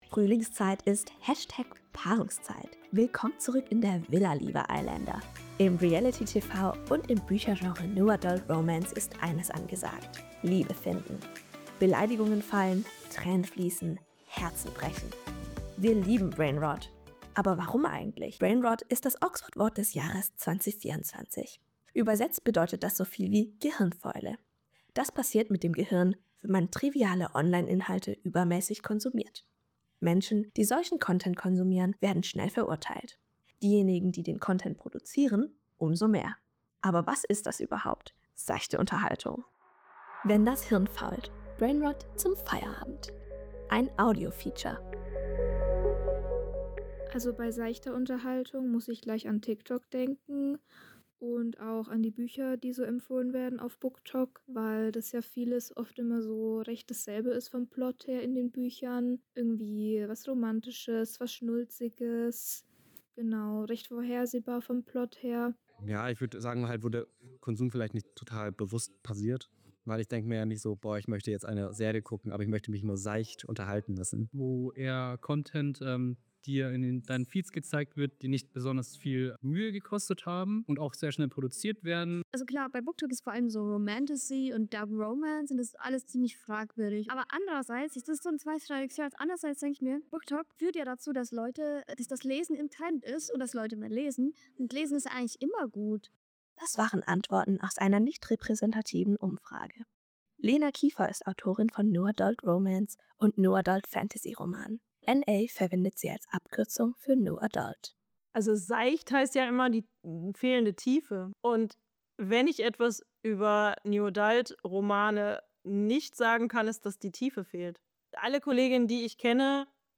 Wenn-das-Hirn-fault.-Audio-Feature.mp3